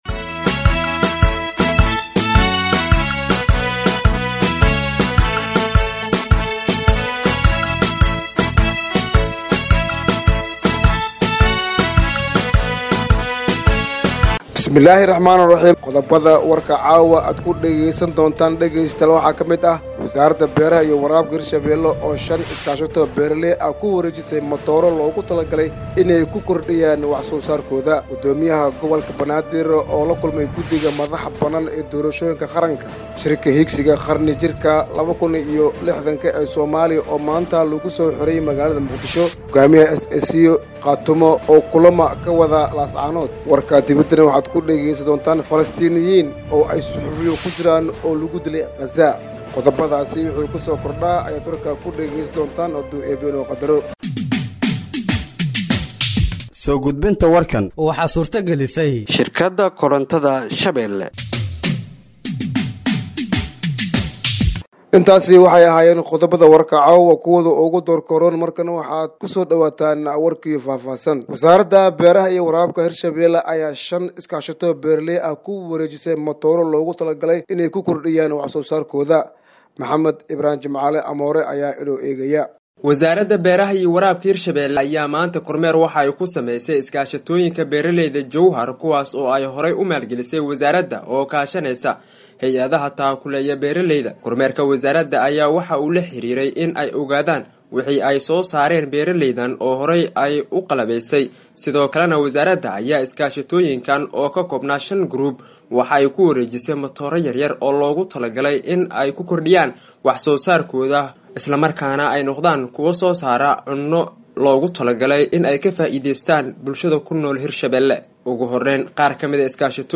Dhageeyso Warka Habeenimo ee Radiojowhar 26/12/2024